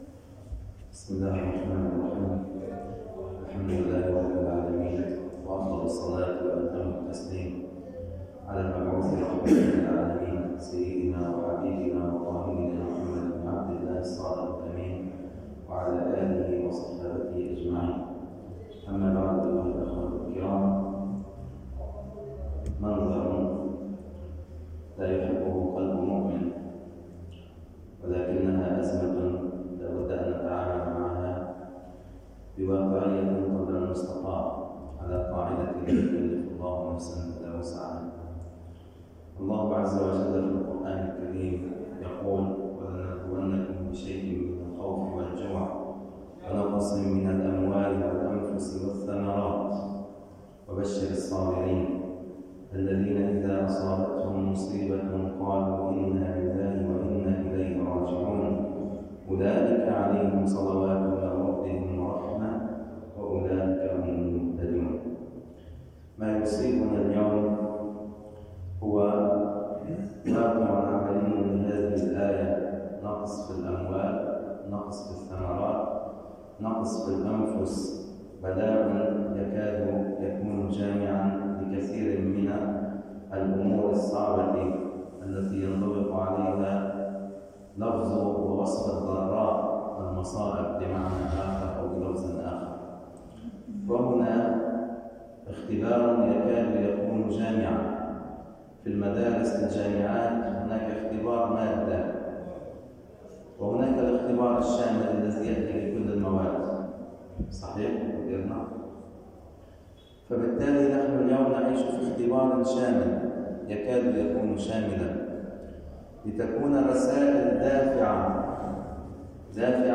[منبر الجمعة]